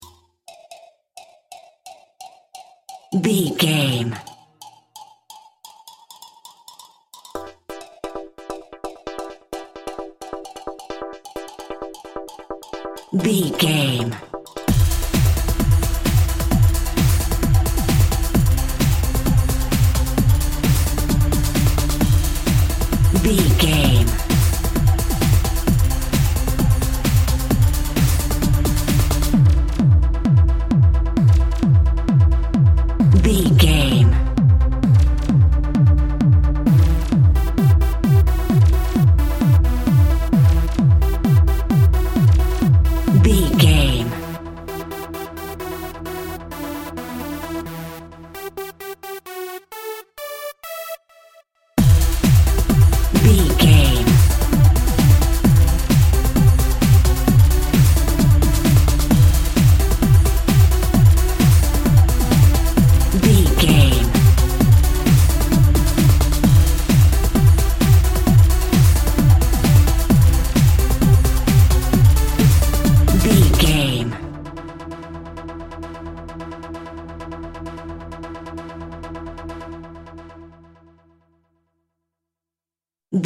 Aeolian/Minor
high tech
uplifting
futuristic
hypnotic
dreamy
smooth
synthesiser
drum machine
house
techno
trance
electro
dance music
synth drums
synth leads
synth bass